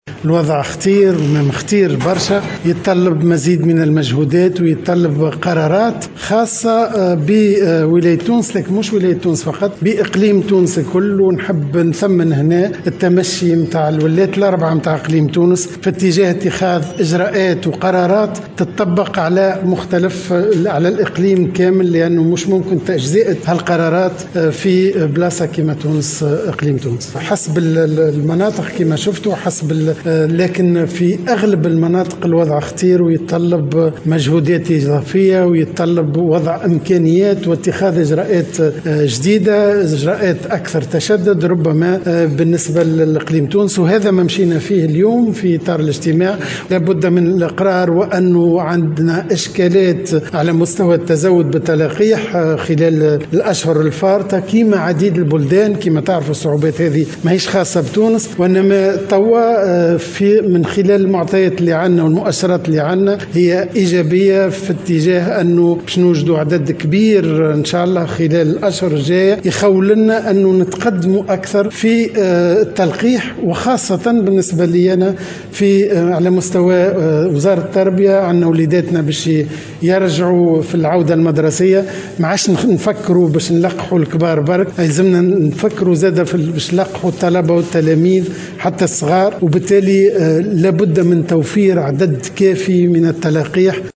أكد وزير التربية فتحي السلاوتي في تصريح لمراسل الجوهرة "اف ام" أن الوضع الوبائي مازال خطيرا ويتطلب قرارات و اجراءات تهم اقليم تونس الكبرى مثمنا تمشي الولاة في تونس في اتجاه اتخاذ قرارات تطبق على كامل الإقليم.